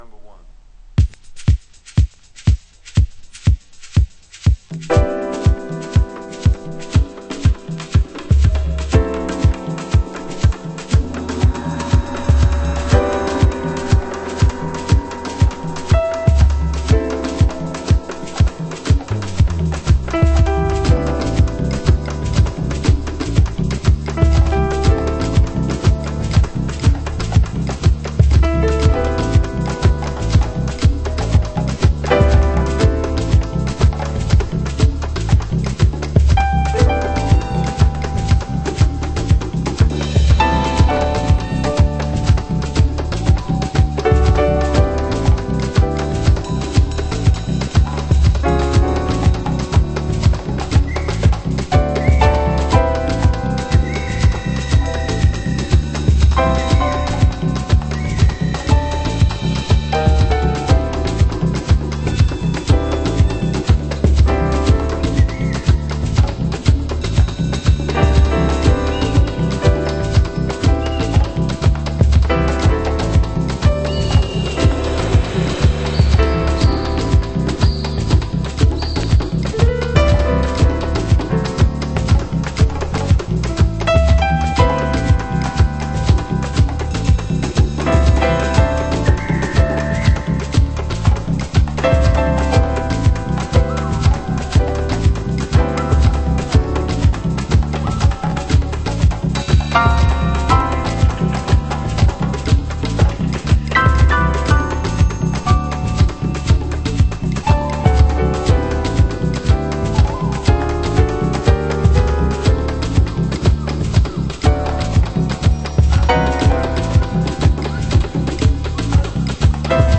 HOUSE MUSIC
Alternate Piano Version